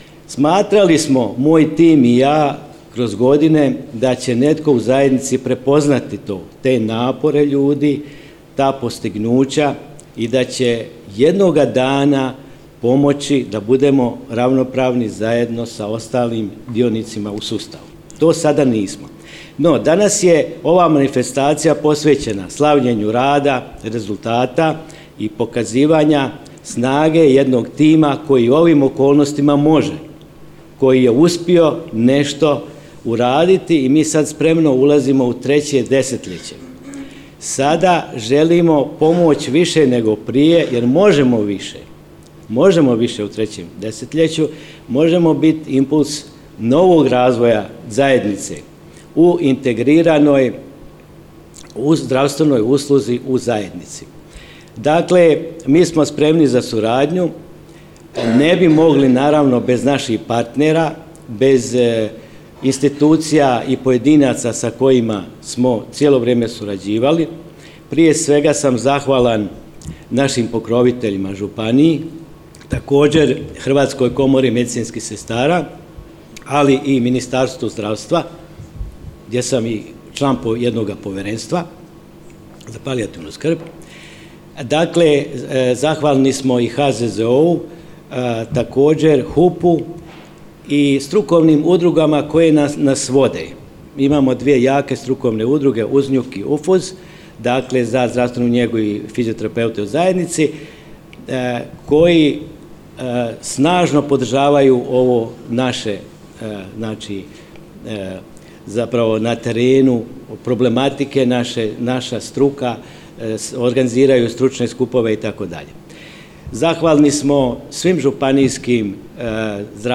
Prigodnom svečanosti u Sisku, pod pokroviteljstvom Sisačko-moslavačke županije i Hrvatske komore medicinskih sestara, zdravstvena ustanova Zrinus iz Petrinje obilježila je 20 godina rada.